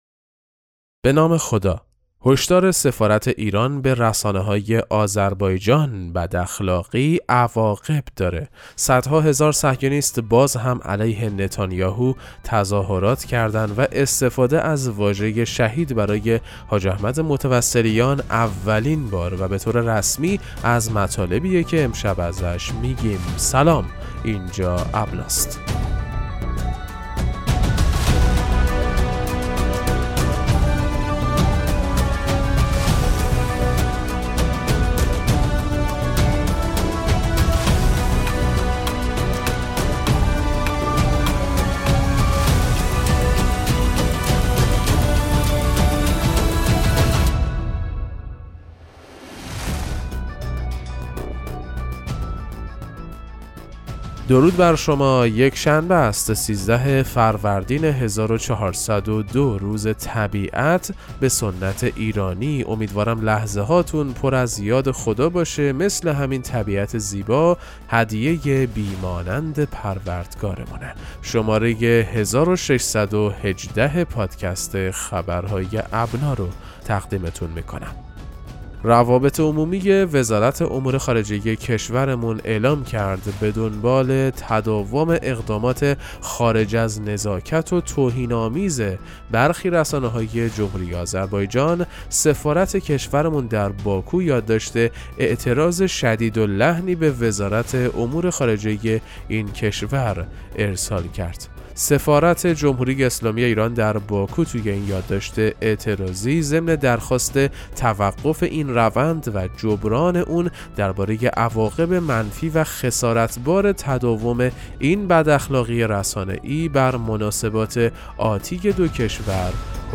پادکست مهم‌ترین اخبار ابنا فارسی ــ 13 فروردین 1402